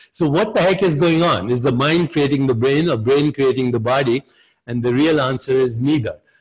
editing a loud audio recording
I just did an interview over zoom and my guest came in very loud. I thought it was just that I had my speaker turned on way too loud but I learned that I didn’t. The guest just had his mic turned up too loud.
My guess is that they are overloading their microphone - if that’s the case then it can’t be fixed.
I think I’m hearing some distortion which you won’t be able to remove but reducing the high frequencies will reduce the distortion.
The good news is, it’s clearly intelligible.
Step one, there are no sound tones higher pitch than 12,000Hz.
It’s clearly overloading the sound channel, and yet, there’s no evidence of distortion in the blue waves [puzzled puppy look].
It sounds like a “communications” radio channel.
It may have two channels, two blue waves, but it’s not stereo. The two channels are identical.